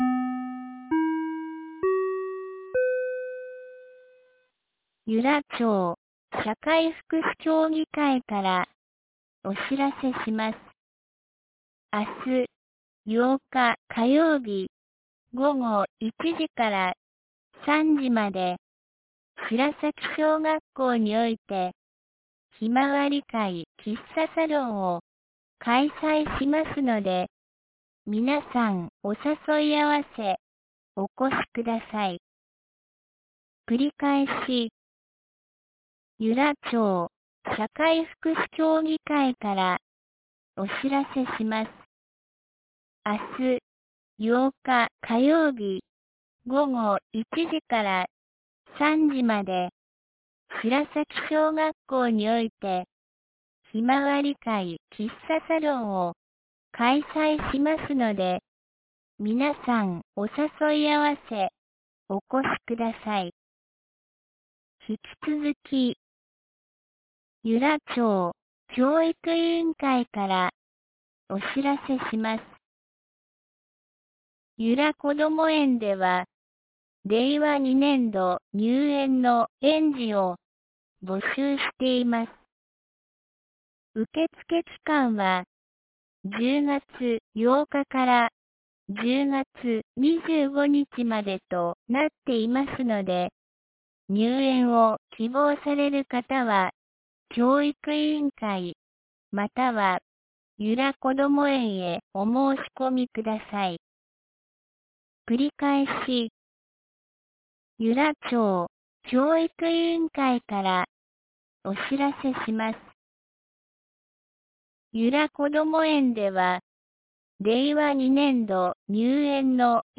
2019年10月07日 17時13分に、由良町から全地区へ放送がありました。
放送音声